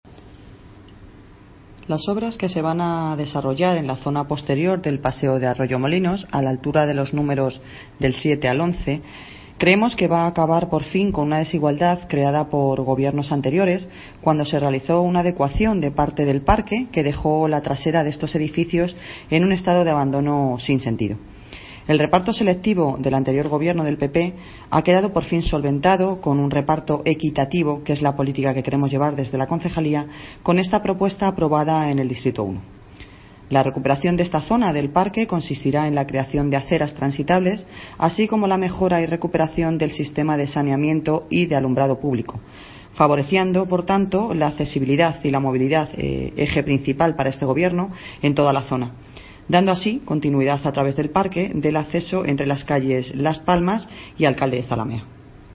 Audio - Noelia Posse (Concejal de Obras, Infraestructuras, Mantenimiento de vías públicas y Festejos)